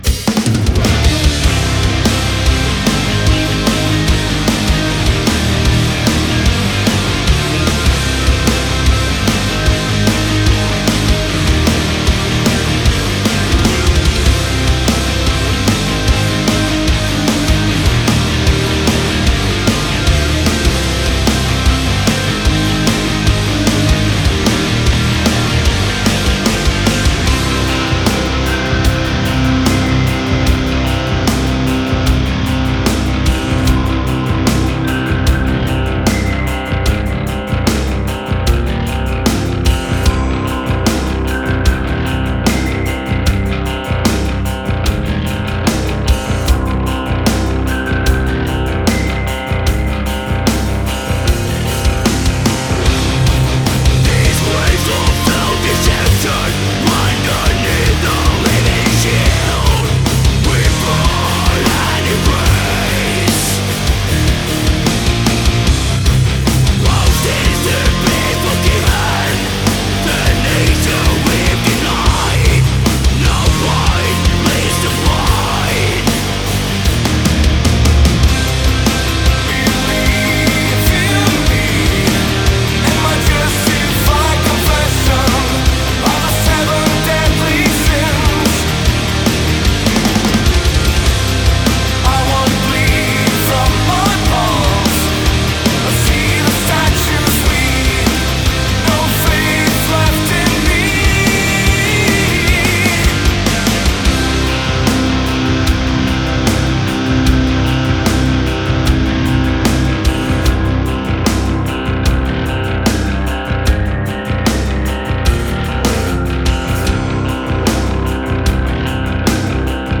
мелодичный дэт-метал или мелодичный дарк-метал.
— «меланхоличный скандинавский метал»).
вокал, гитара, ударные, клавишные
бас-гитара